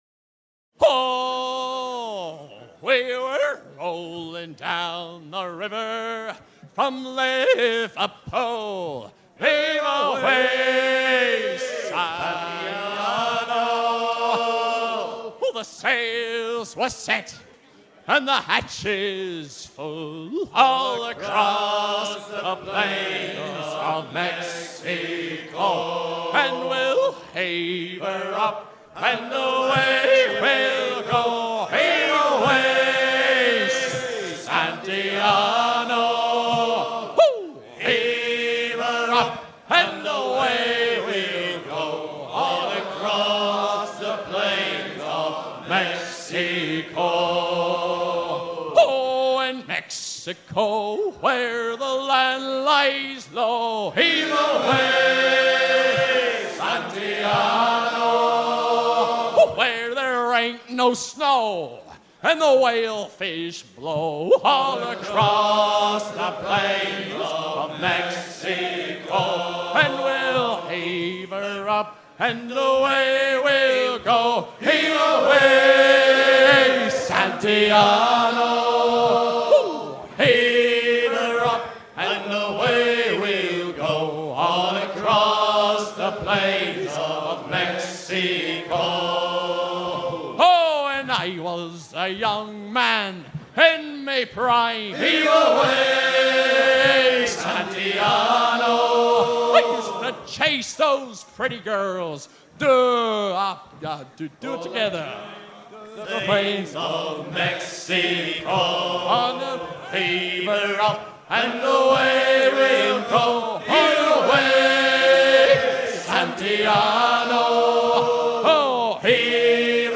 Fonction d'après l'analyste gestuel : à virer au cabestan
Usage d'après l'analyste circonstance : maritimes
Chants de marins en fête - Paimpol 1999
Catégorie Pièce musicale éditée